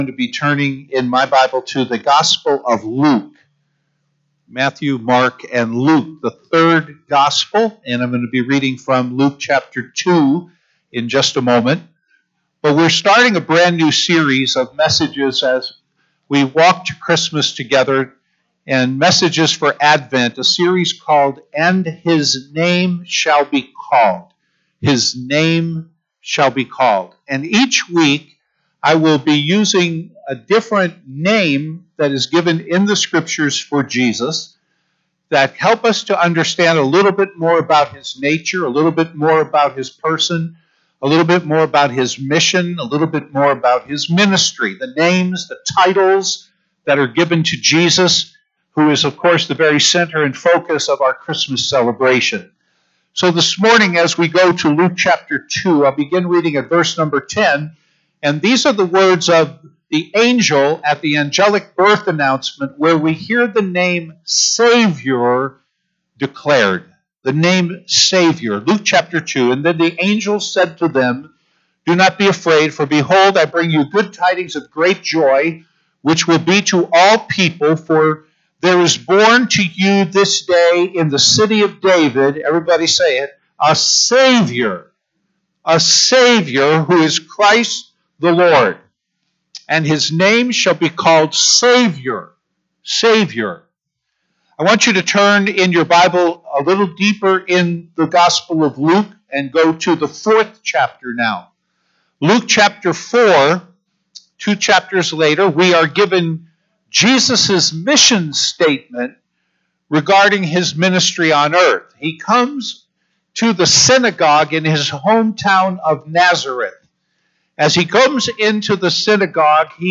Messages preached in the year 2021